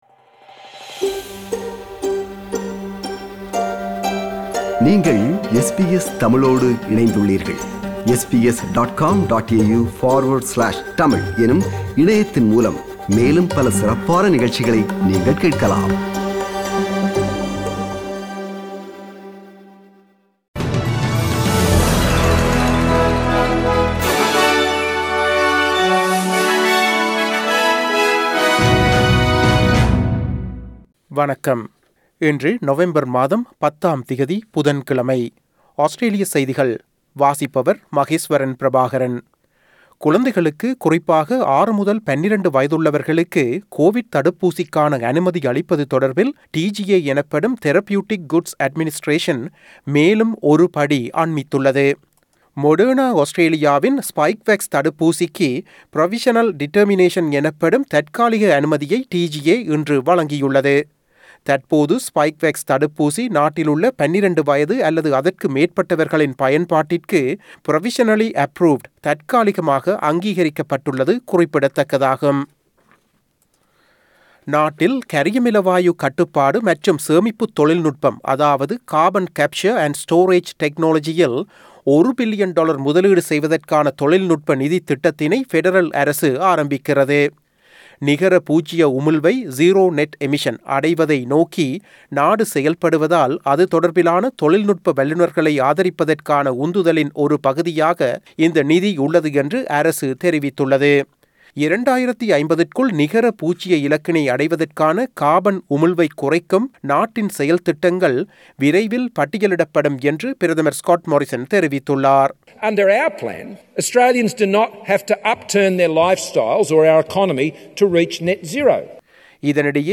Australian news bulletin for Wednesday 10 November 2021.